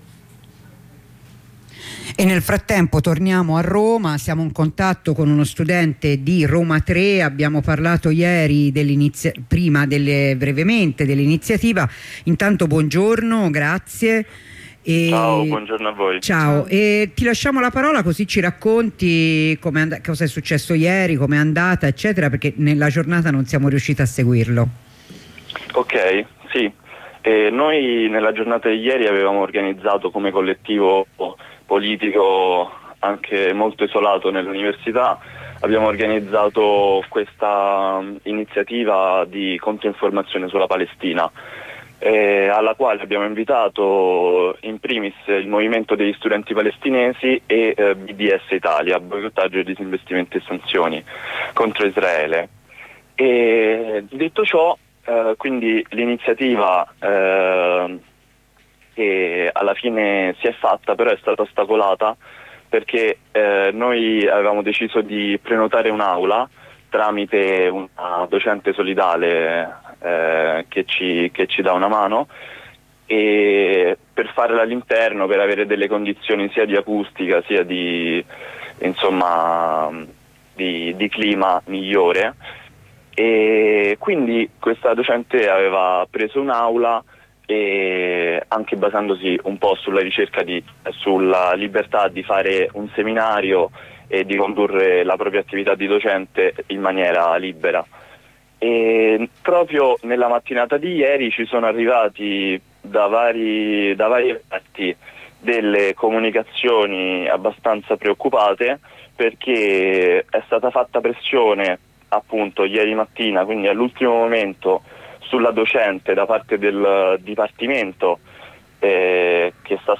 Il resoconto di un compagno del colletivo